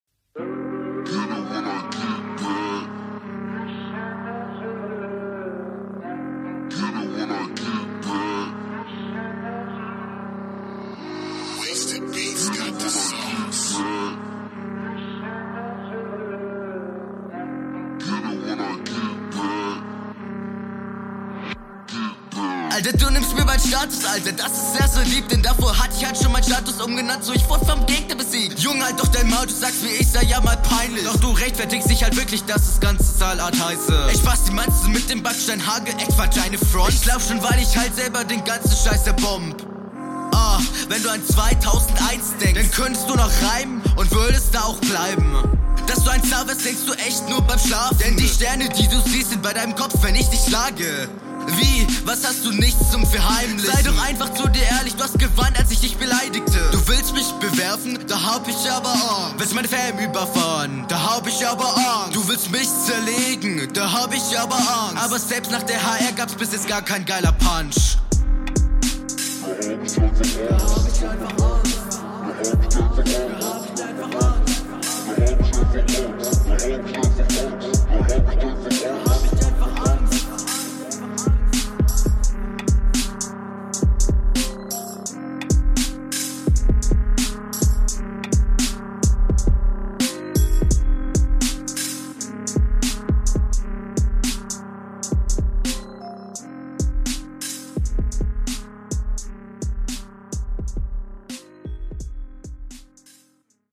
Flow ist auch besser als in der HR3.
Flowlich verhaspelst du dich hin und wieder etwas, vorallem in den schnelleren Passagen.